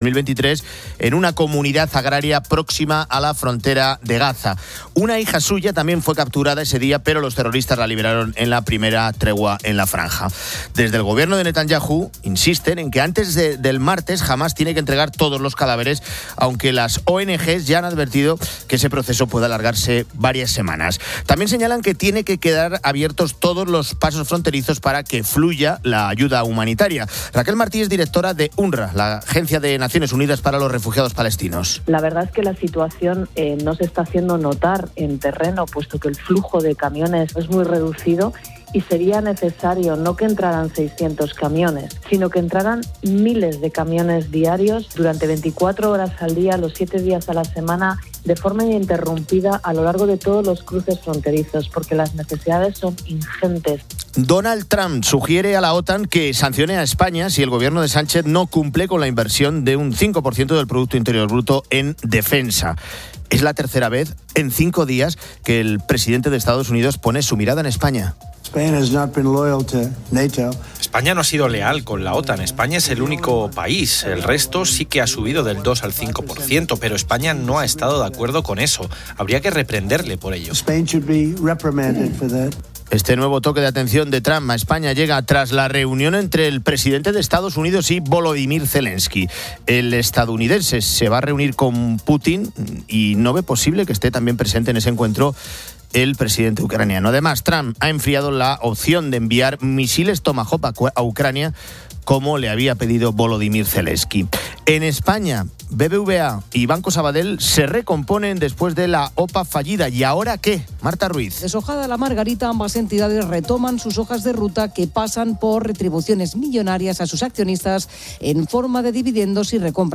Fin de Semana 10:00H | 18 OCT 2025 | Fin de Semana Editorial de Cristina López Schlichting.